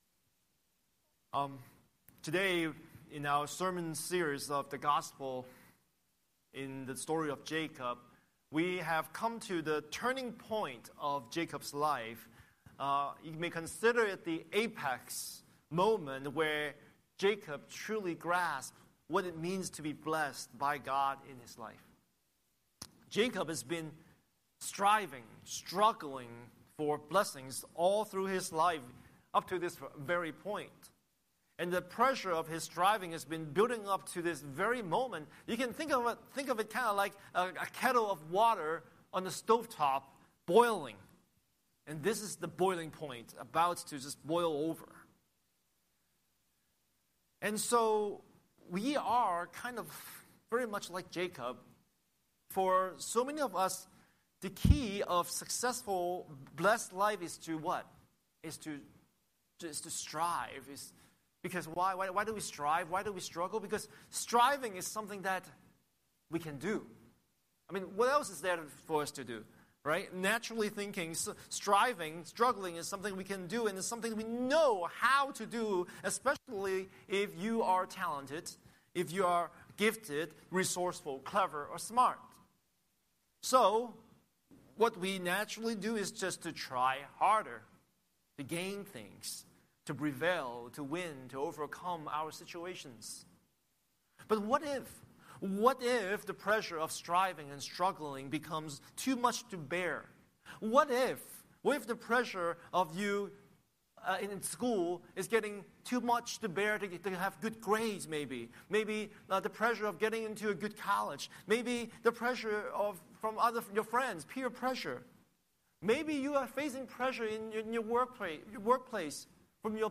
Scripture: Genesis 32:22–32 Series: Sunday Sermon